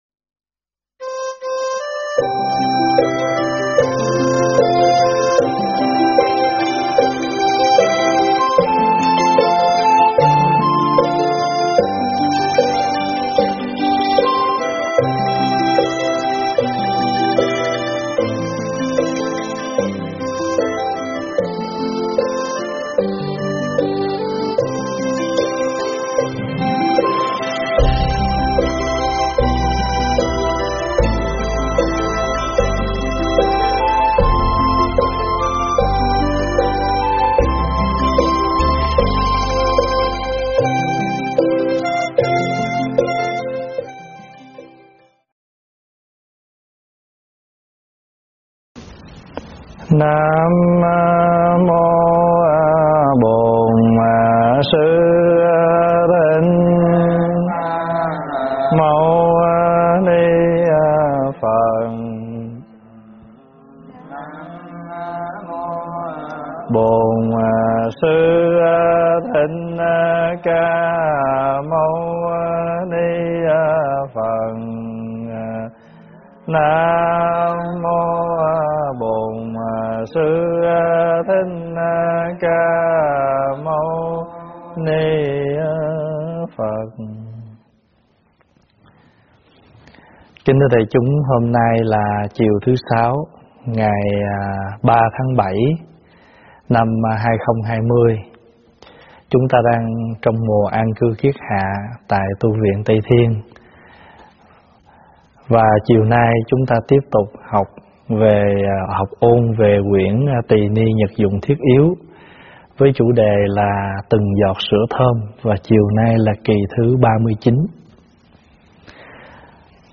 Mp3 thuyết pháp Từng Giọt Sữa Thơm 39 - Kệ thọ thực
giảng tại Tv Tây Thiên